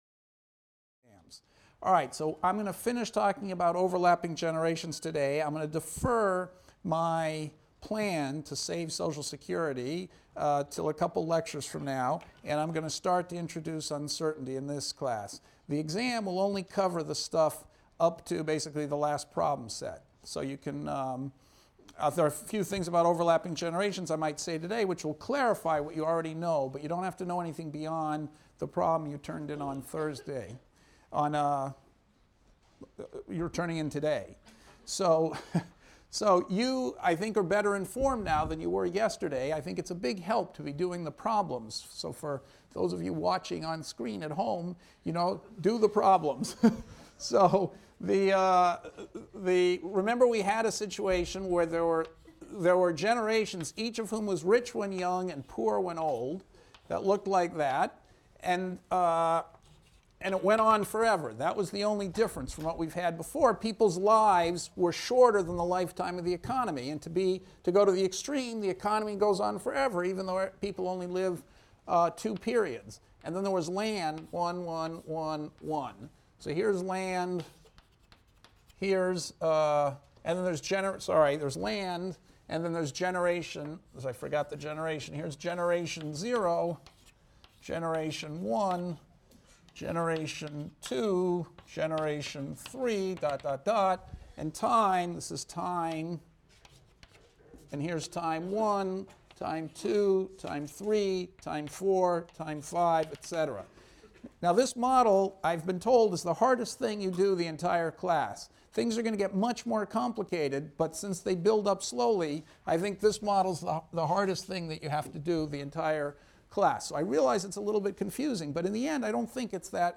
ECON 251 - Lecture 13 - Demography and Asset Pricing: Will the Stock Market Decline when the Baby Boomers Retire?